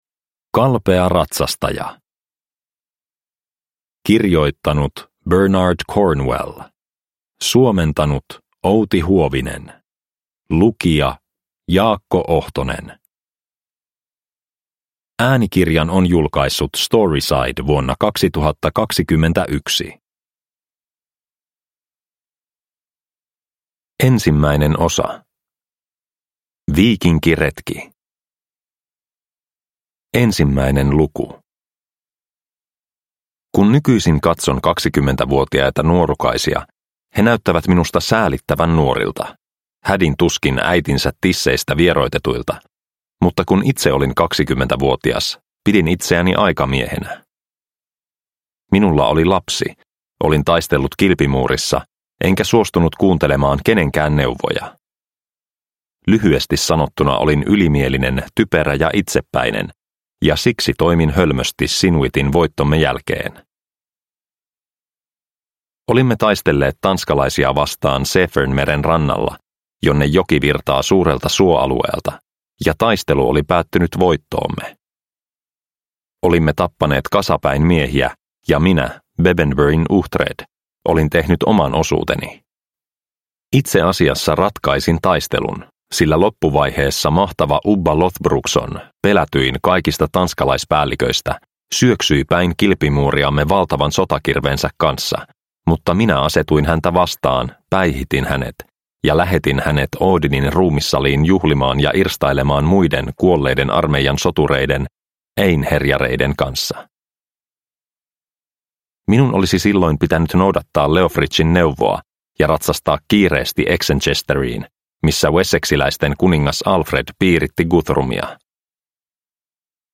Kalpea ratsastaja – Ljudbok – Laddas ner